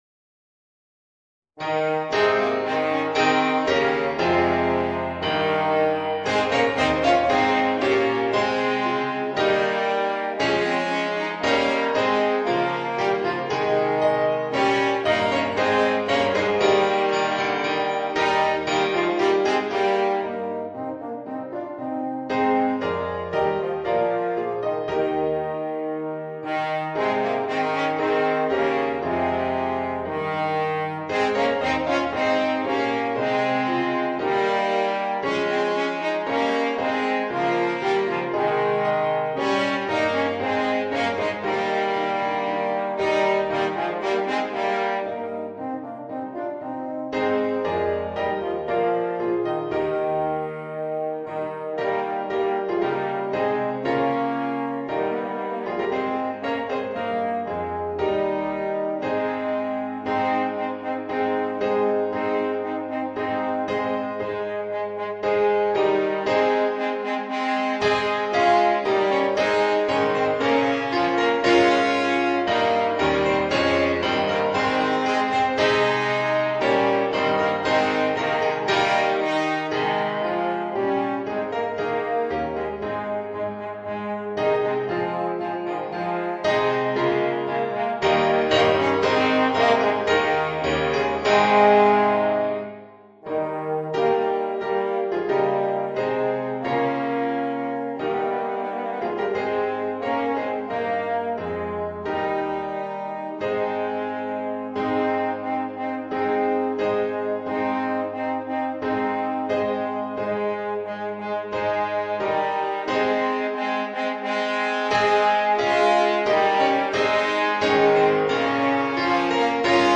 Voicing: 2 Eb Horns and Organ